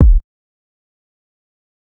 Metro Kicks [Bricks].wav